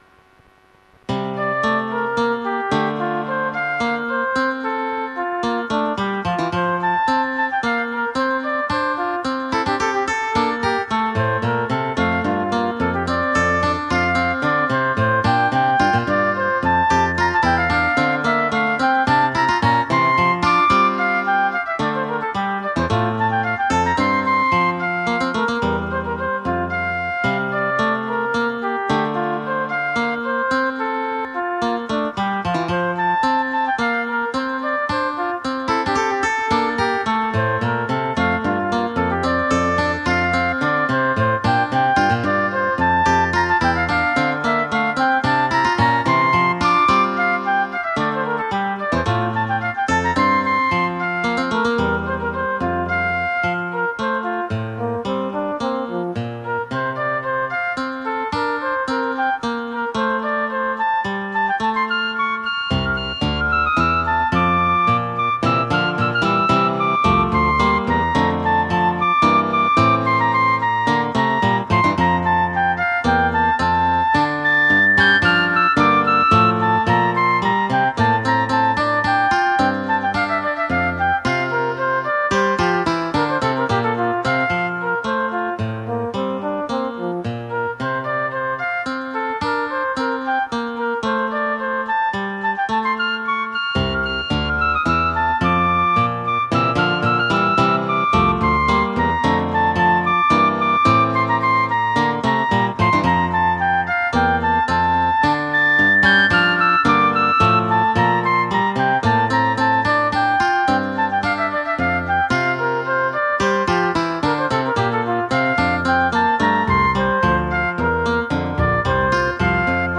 Duettino II for flute & guitar
BAROQUE MUSIC ; POLYPHONIC MUSIC